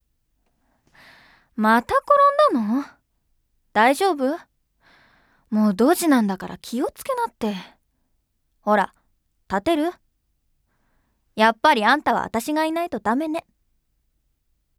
• セリフ01
③女の子.wav